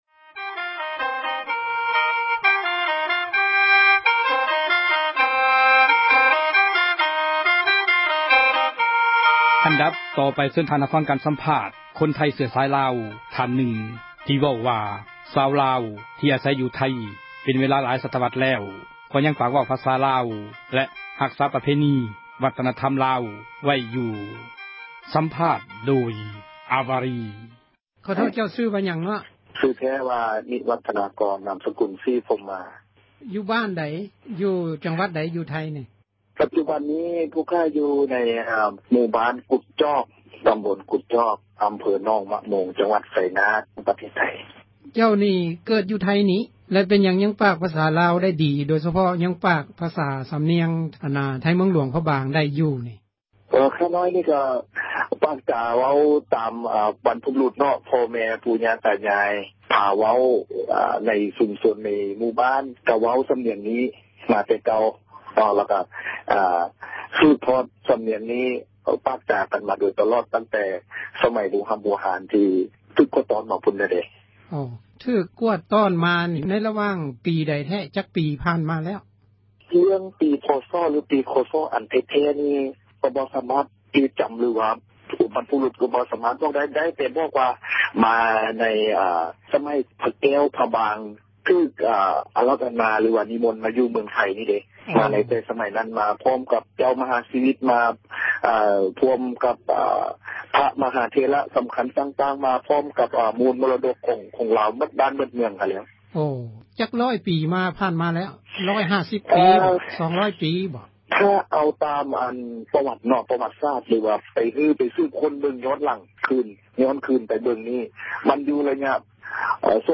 ສັມພາດຄົນໄທ ເຊື້ອສາຍລາວ